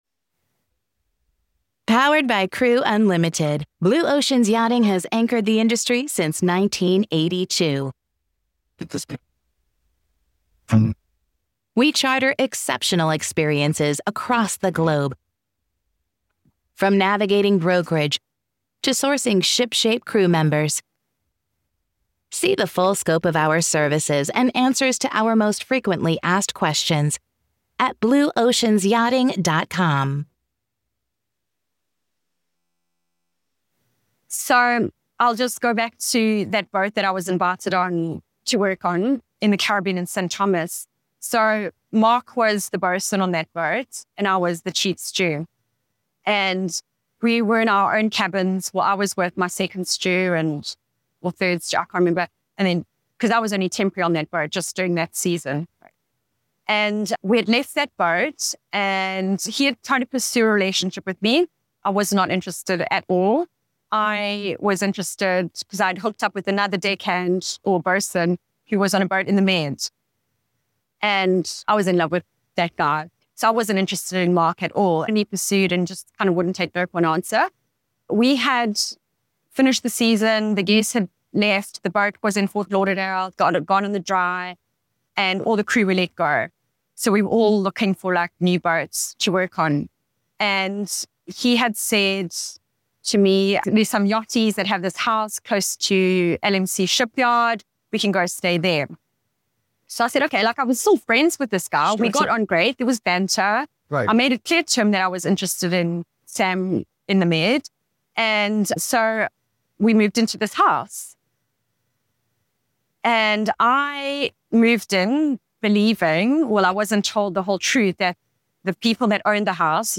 📍 Location Mahekal® Beach Resort — Playa del Carmen, Mexico